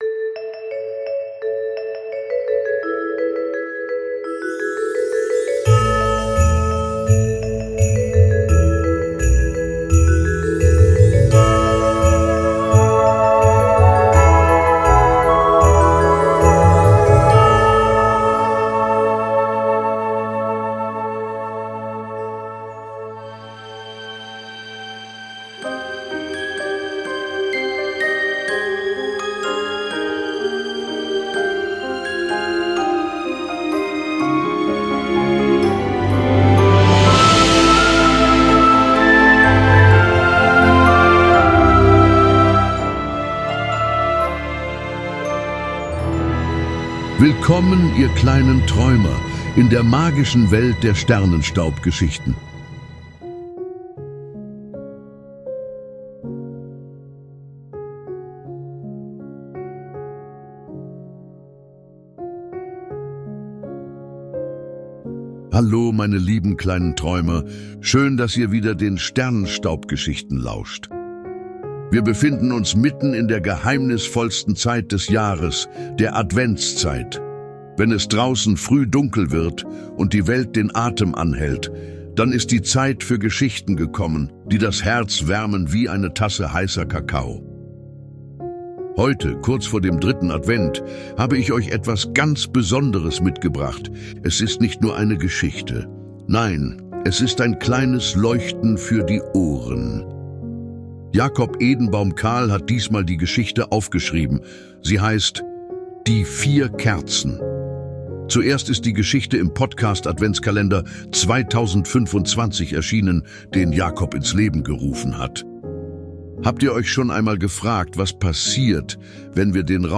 Ein magisches Gemeinschaftswerk mit vielen Stimmen für kleine und große Träumer...